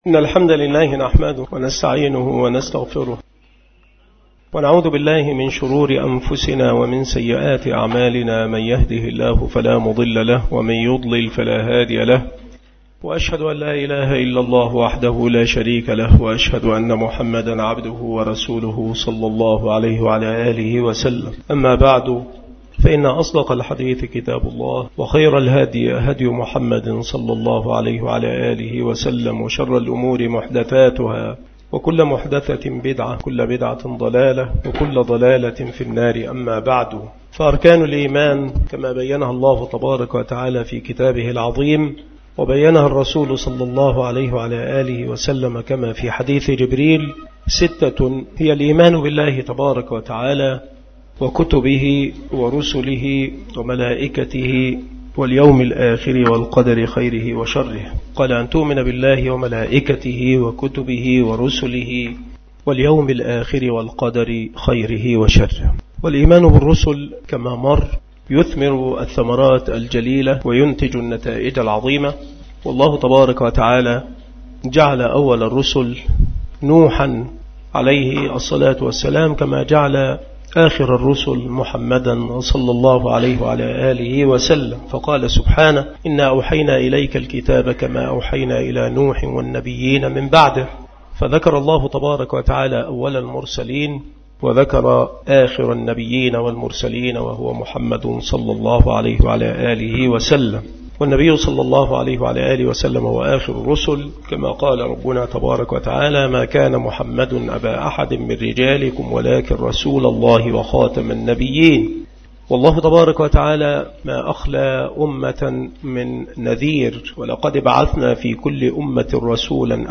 مكان إلقاء هذه المحاضرة من دروس معهد الفرقان لإعداد الدعاة بالمسجد الشرقي بسبك الأحد - أشمون - محافظة المنوفية - مصر